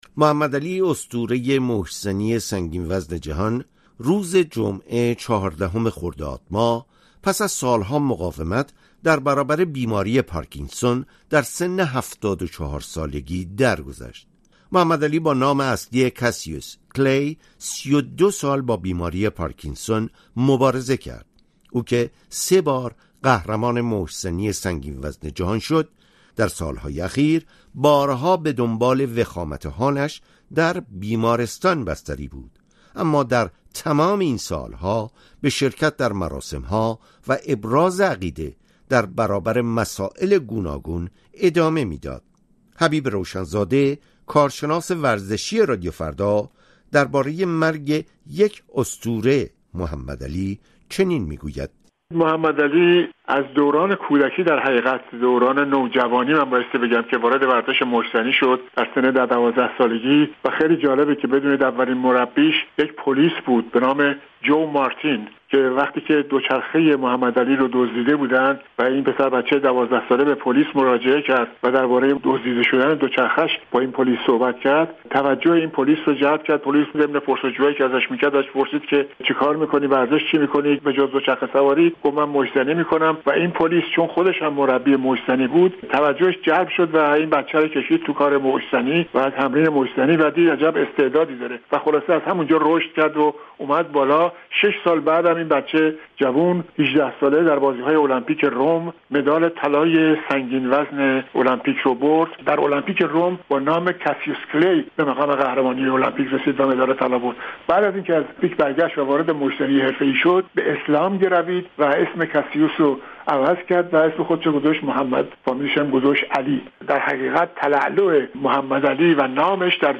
حبیب روشن‌زاده که طی بیش از نیم قرن، گزارش‌های مهم ورزشی ایران و جهان با صدای او به خانه ایرانیان آمده، در گفت‌وگو با رادیو فردا از خاطره محبوبیت محمد علی در ایران می‌گوید: